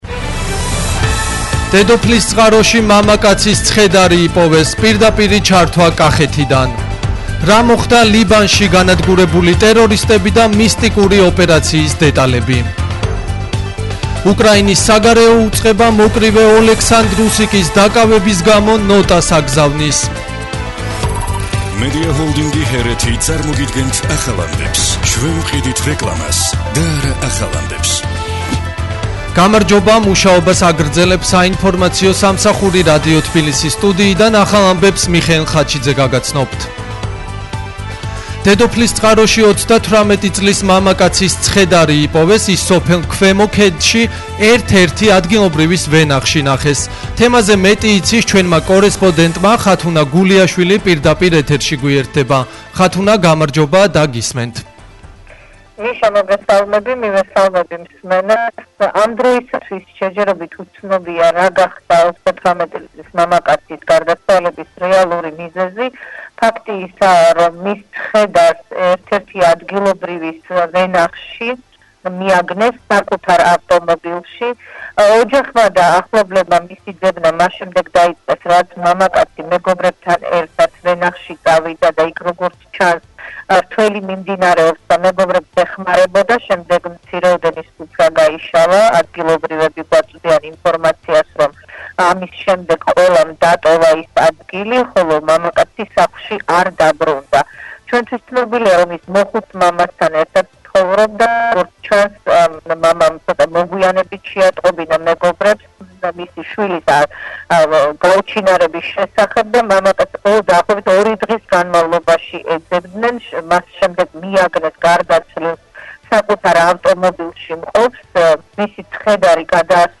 ახალი ამბები
• დედოფლისწყაროში მამაკაცის ცხედარი იპოვეს – პირდაპირი ჩართვა კახეთიდან